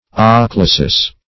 Meaning of ochlesis. ochlesis synonyms, pronunciation, spelling and more from Free Dictionary.
Search Result for " ochlesis" : The Collaborative International Dictionary of English v.0.48: Ochlesis \Och*le"sis\, n. [NL., fr. Gr.